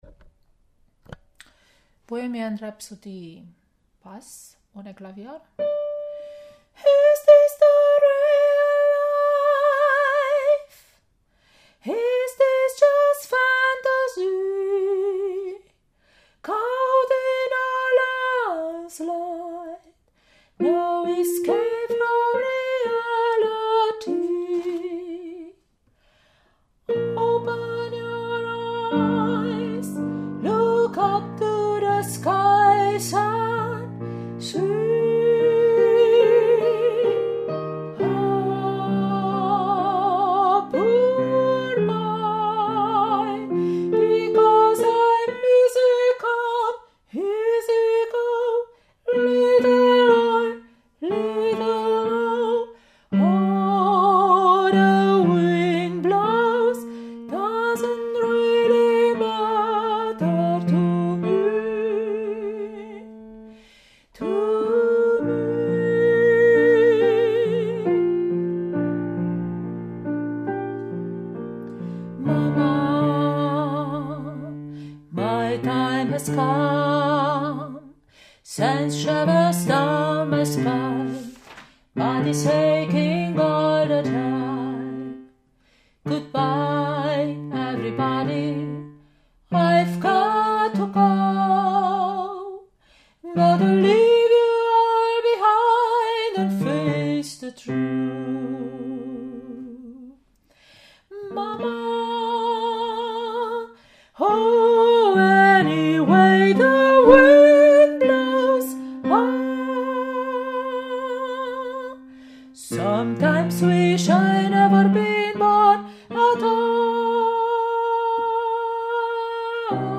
Bohemian Rhapsody – Bass ohne Klavier
BR-ohne-Klavier-Bass.mp3